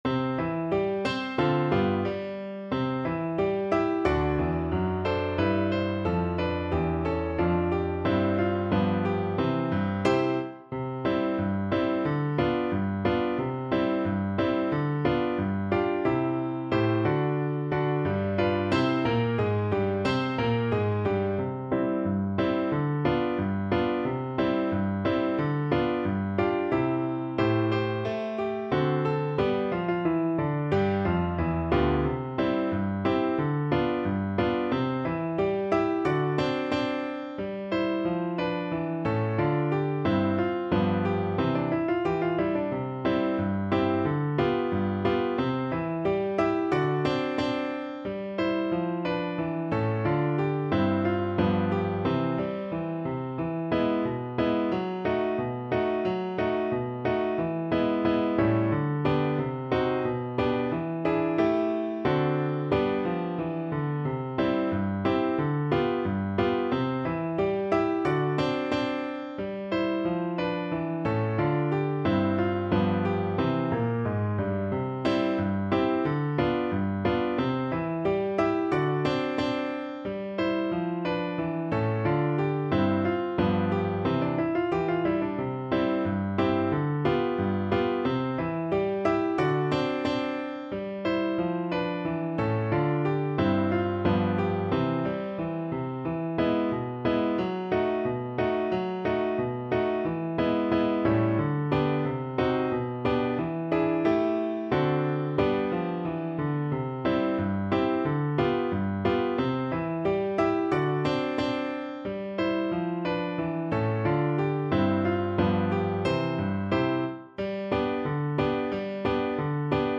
=90 Fast and cheerful
Pop (View more Pop Flute Music)